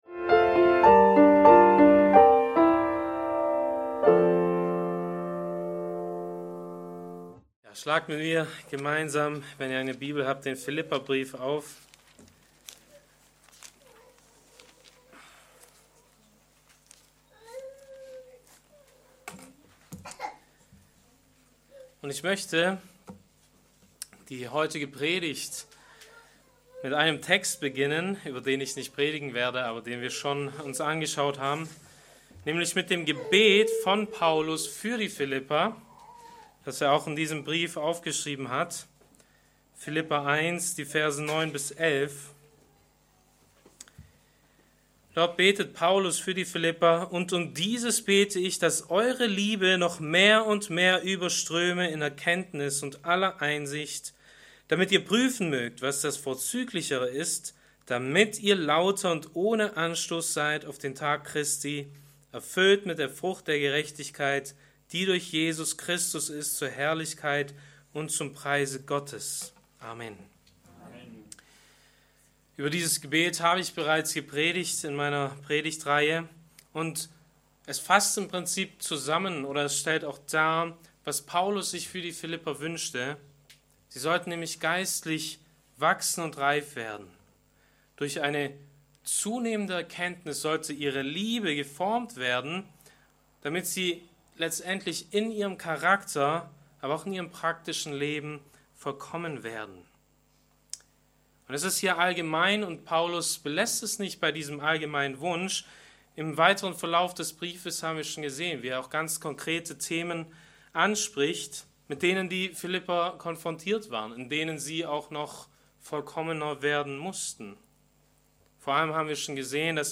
Allein aus Gnade - Bibeltreue Predigten der Evangelisch-Baptistischen Christusgemeinde Podcast